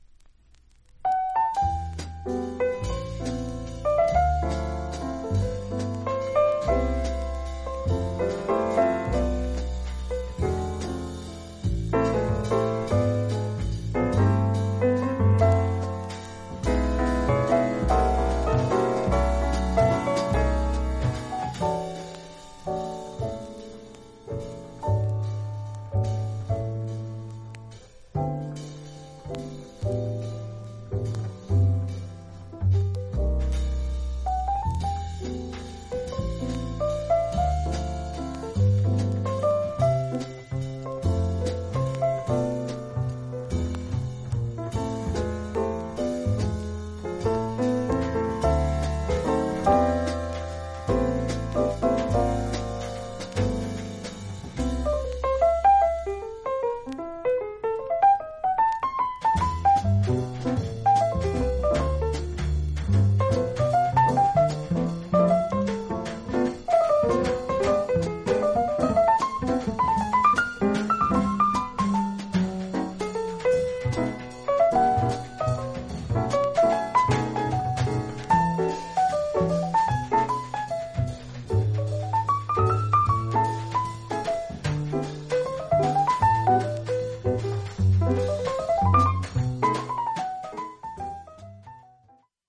実際のレコードからのサンプル↓